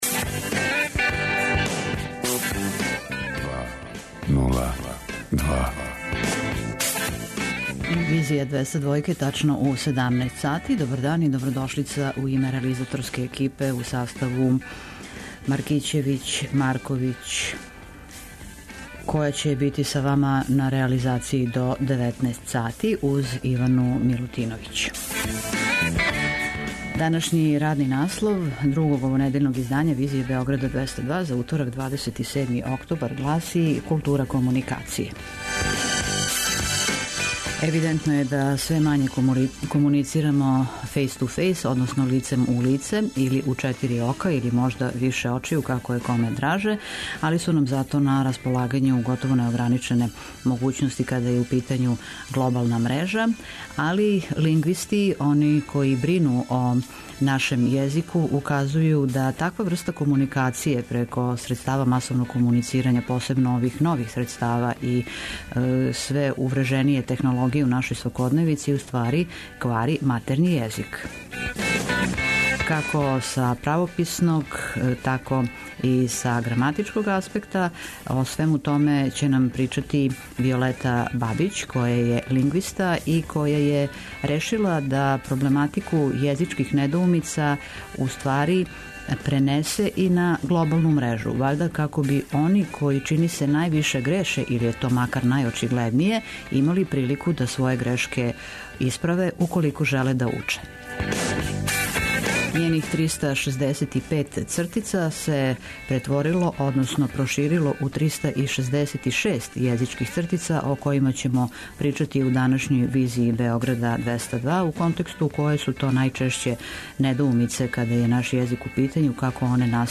преузми : 54.97 MB Визија Autor: Београд 202 Социо-културолошки магазин, који прати савремене друштвене феномене.